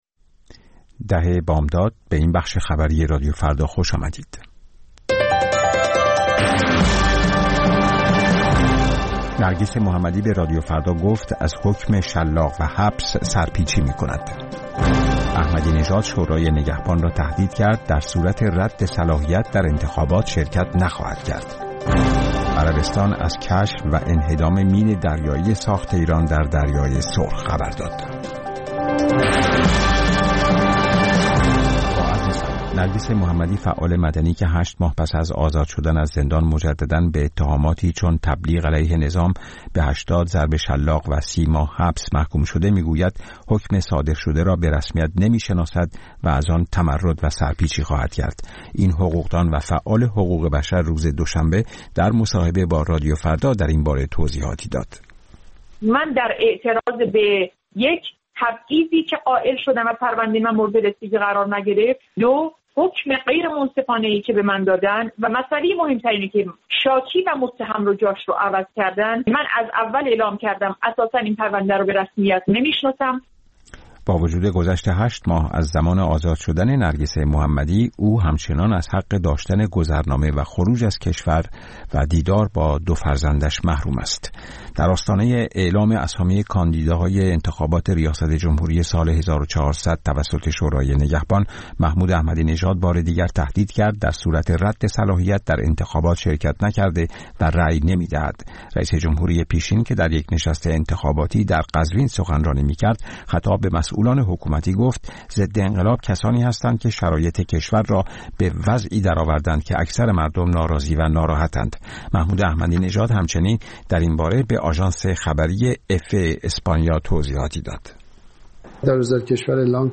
اخبار رادیو فردا، ساعت ۱۰:۰۰